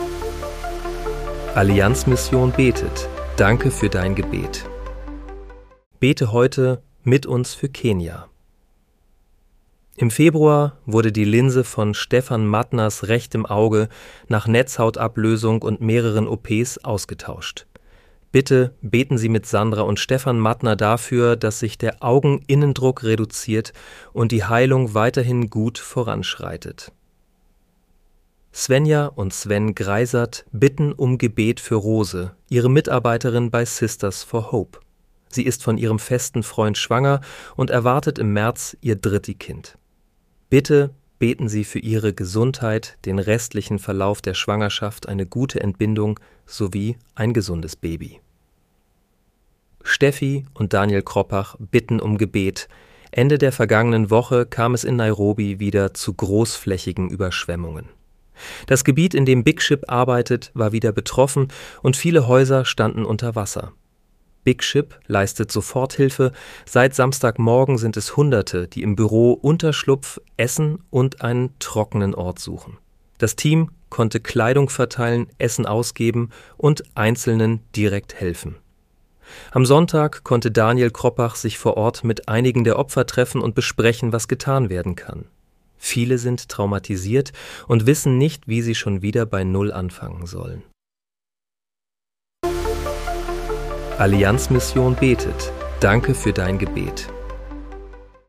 Bete am 14. März 2026 mit uns für Kenia. (KI-generiert mit der